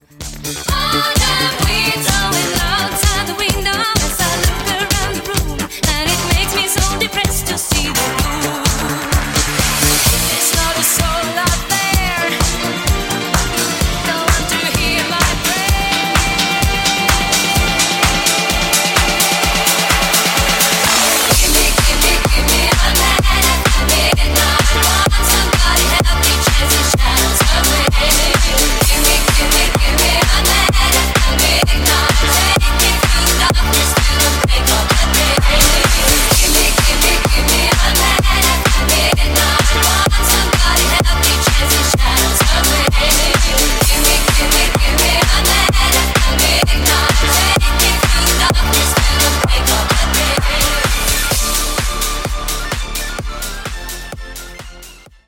Genre: 80's
Clean BPM: 123 Time